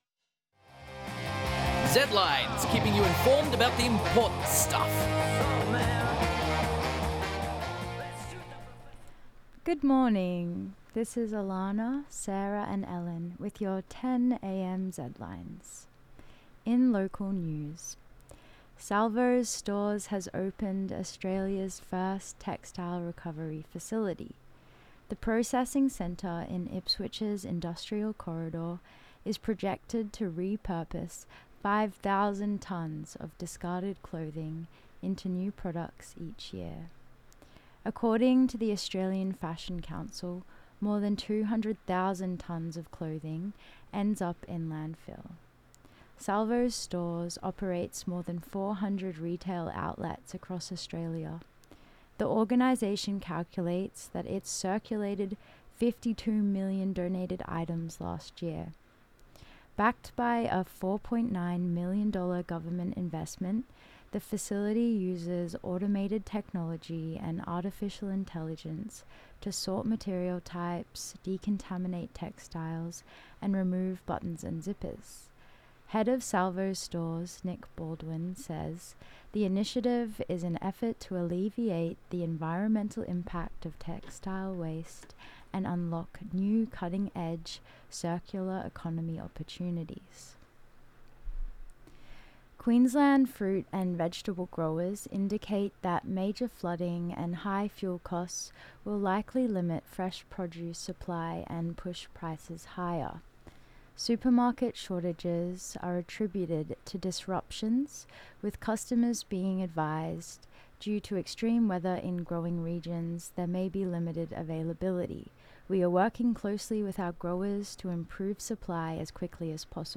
From Wikimedia Commons under CC4.0 Zedlines Bulletin ZedlinesMonday2310am.mp3